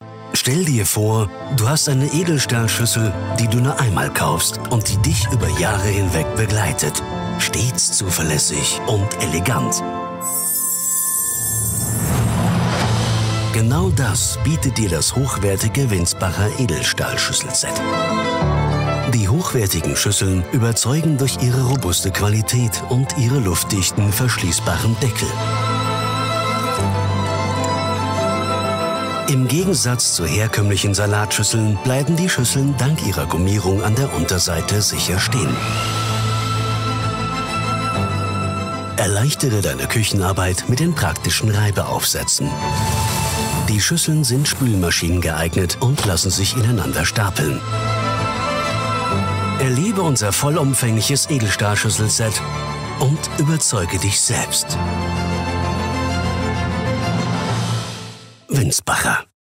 markant, sonore Stimme, kernig Stimmalter: 35-65
Sprechprobe: Werbung (Muttersprache):
If you are looking for a distinctive, sonorous voice, then you have come to the right place.